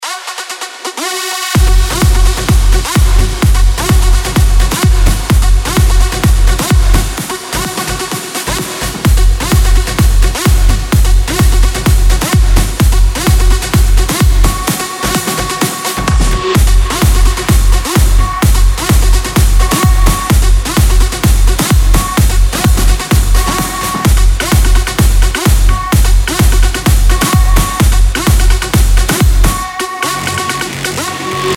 • Качество: 192, Stereo
Крутой ремикс от известного диджея за рубежом